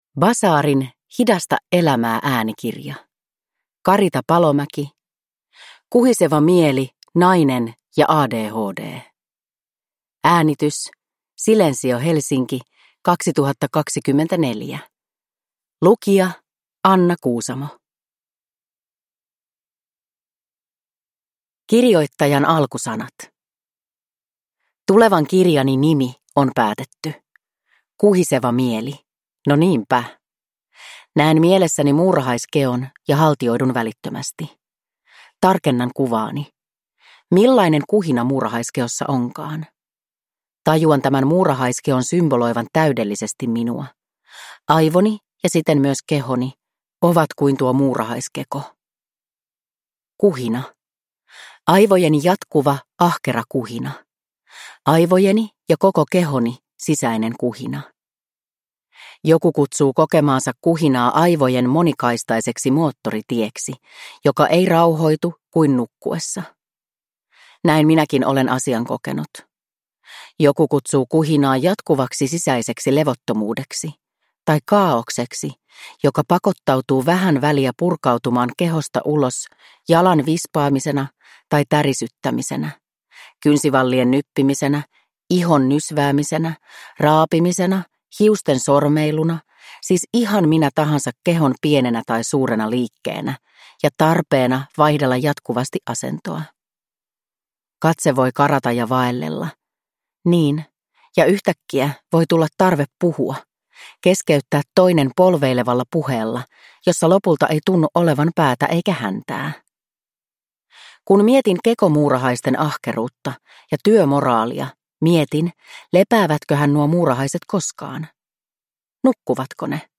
Kuhiseva mieli – nainen ja ADHD – Ljudbok